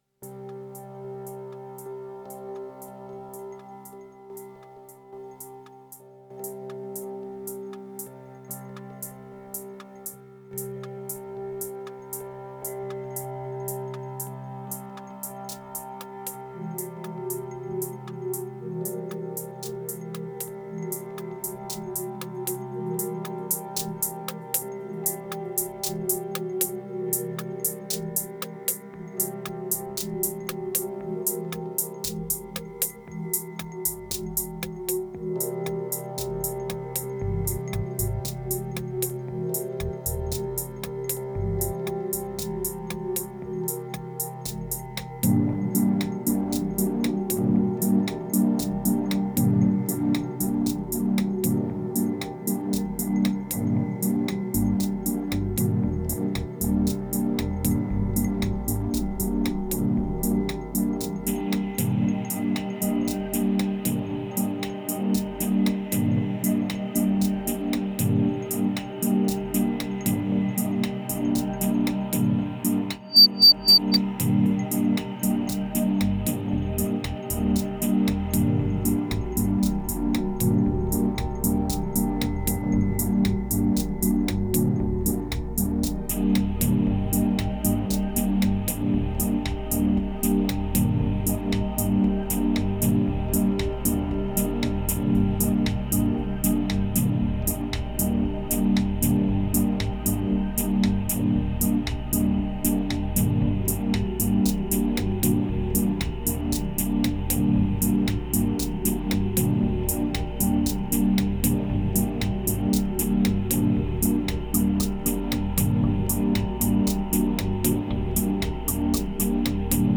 2608📈 - 59%🤔 - 116BPM🔊 - 2017-09-11📅 - 308🌟